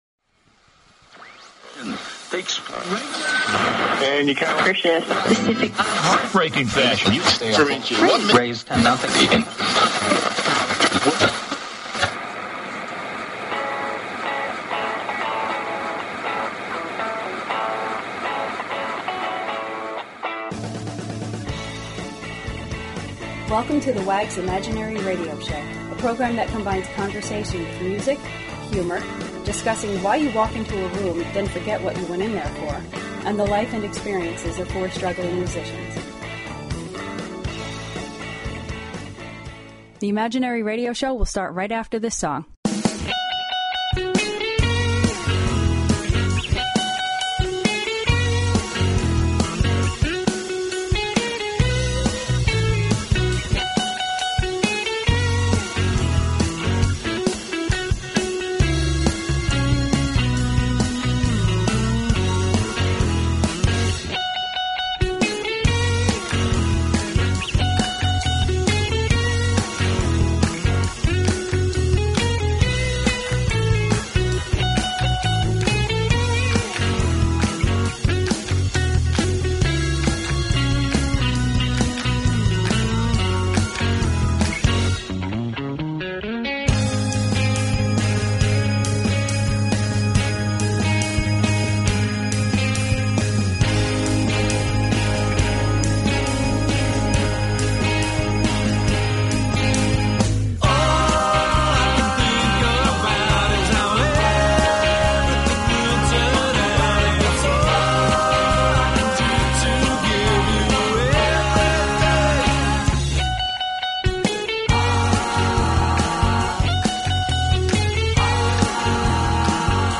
Talk Show Episode, Audio Podcast, The_Wags_Imaginary_Radio_Show and Courtesy of BBS Radio on , show guests , about , categorized as
It is a podcast that combines conversation with music, humor & the life experiences of four struggling musicians from New Jersey.